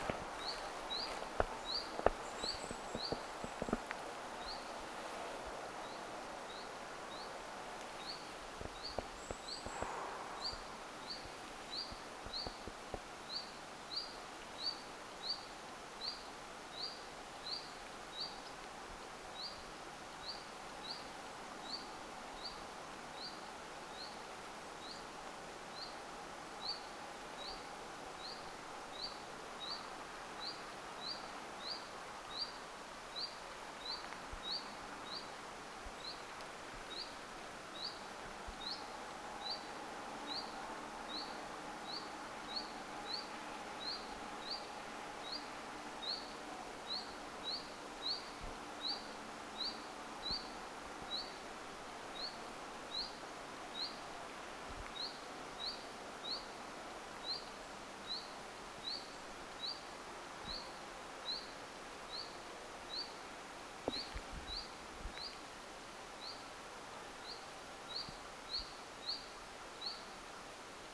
päivän toinen vit-tiltaltti
voi_kai_sanoa_vit-tiltaltti.mp3